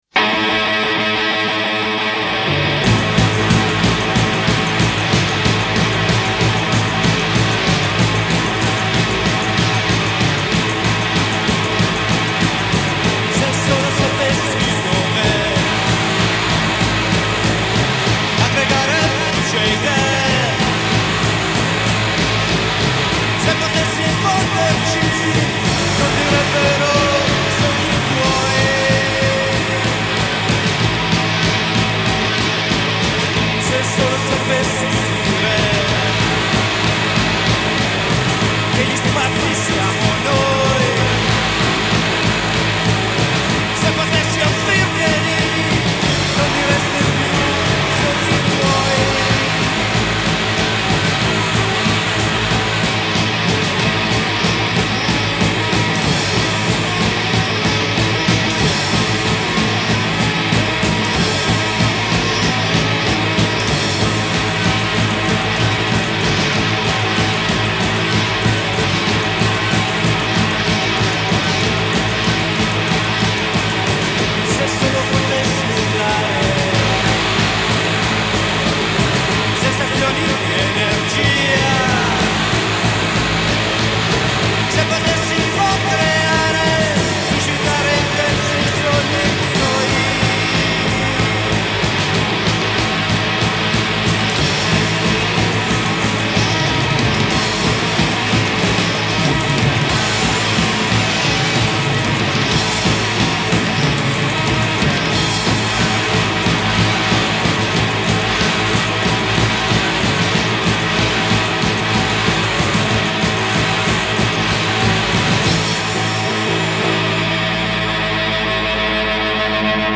alla chitarra
al basso
alla batteria